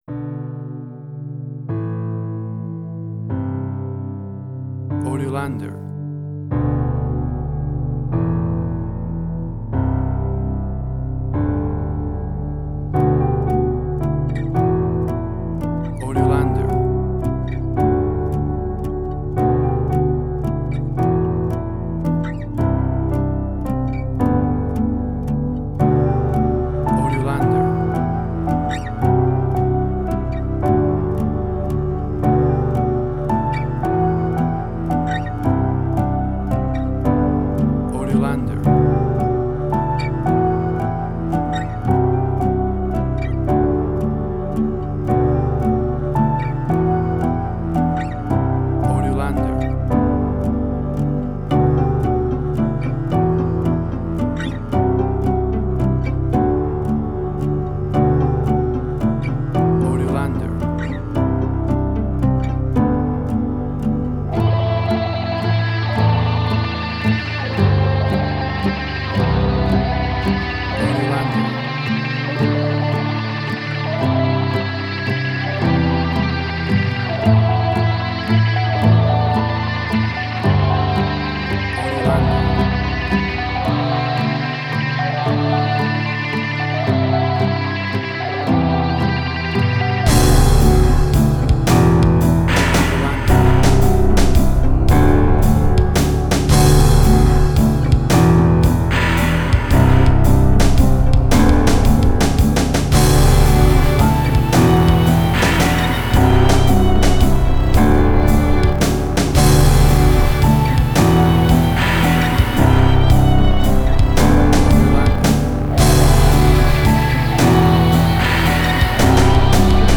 emotional music
Tempo (BPM): 112